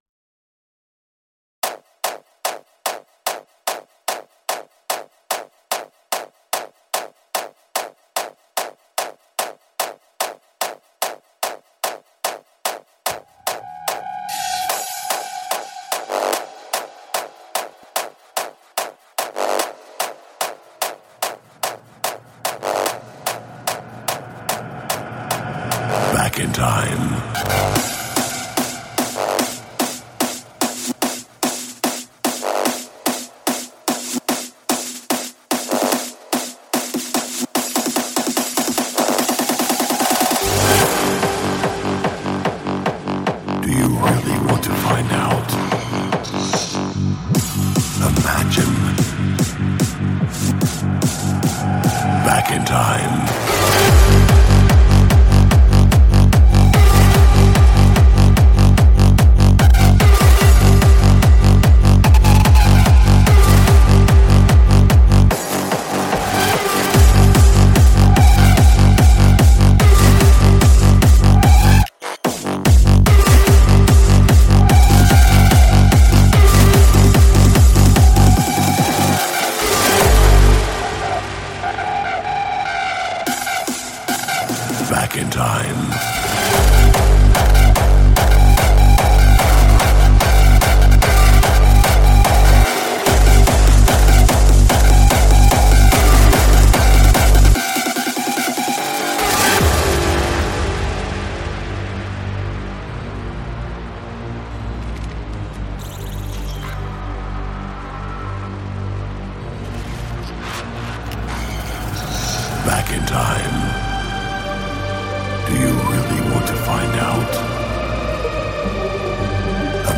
Жанр: Hardstyle